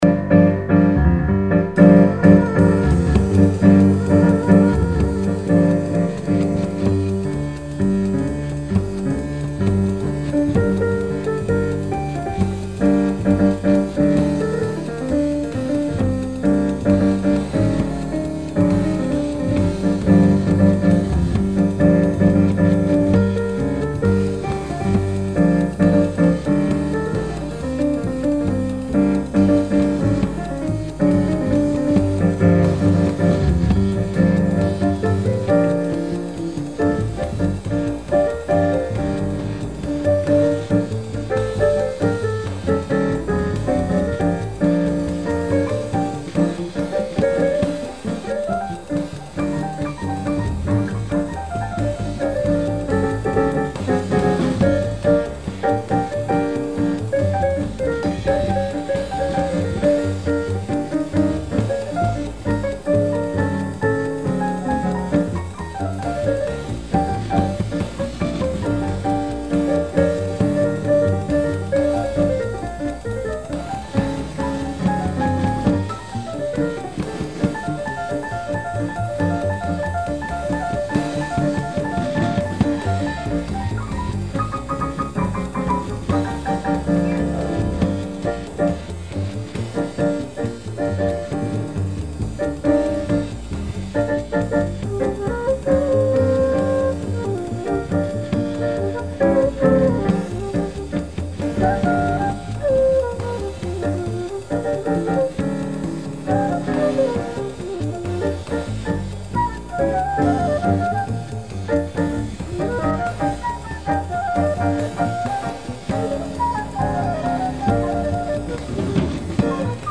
flute
keyboards
bass
drums   Holiday Inn